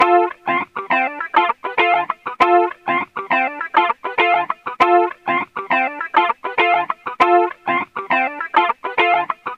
Sons et loops gratuits de guitares rythmiques 100bpm
Guitare rythmique 21